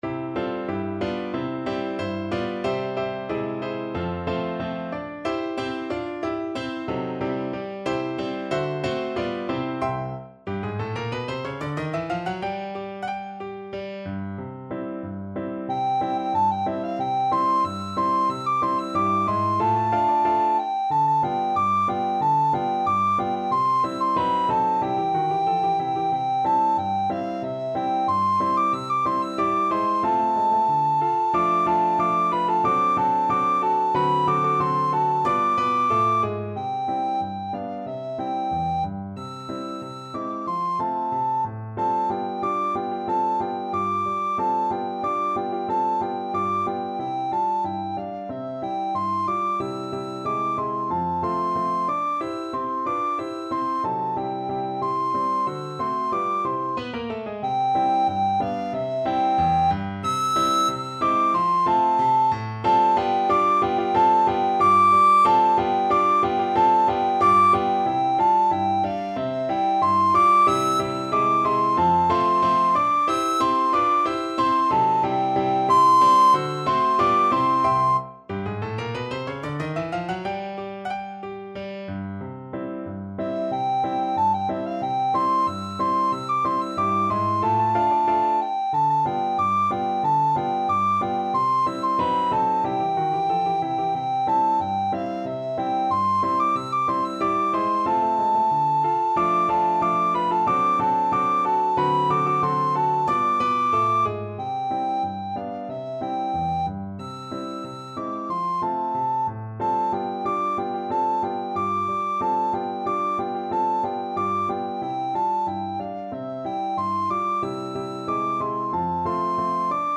Free Sheet music for Soprano (Descant) Recorder
Allegretto =92
2/4 (View more 2/4 Music)
Traditional (View more Traditional Recorder Music)